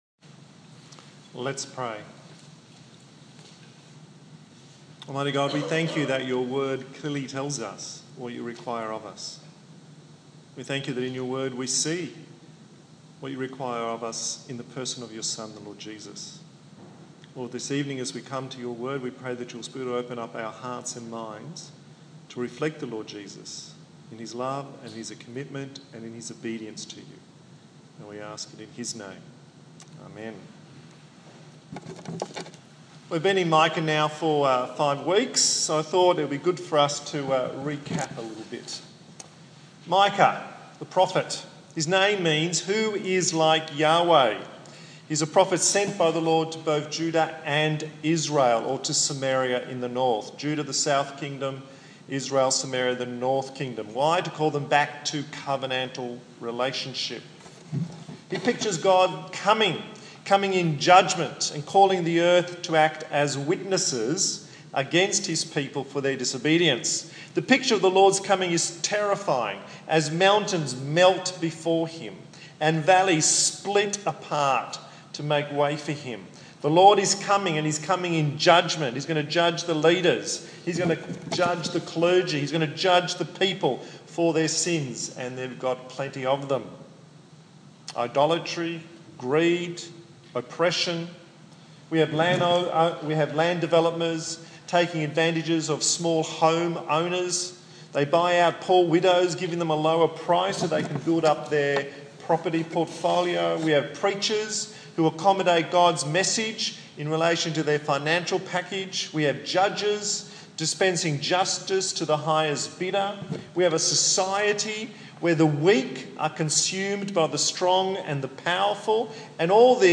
24/05/2015 What God Really Wants Preacher